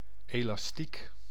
Ääntäminen
Synonyymit gomme élastique Ääntäminen France (Île-de-France): IPA: [ka.ut.ʃu] Haettu sana löytyi näillä lähdekielillä: ranska Käännös Ääninäyte Substantiivit 1. rubber {n} 2. gummi 3. elastiek Suku: m .